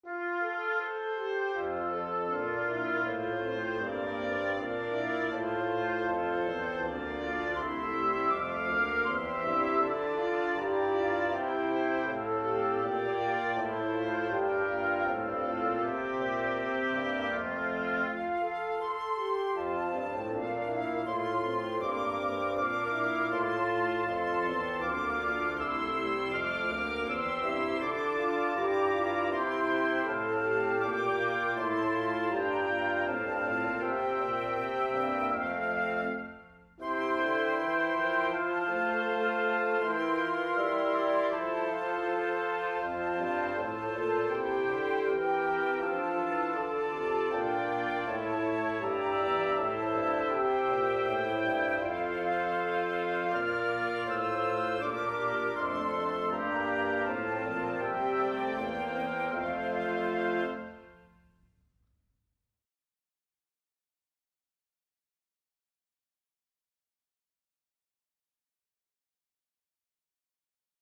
blaaskwintet – mp3